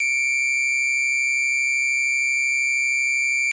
P-5 Radar Pulse | Sneak On The Lot
P-5 Pulse Wave Minor Second, Looped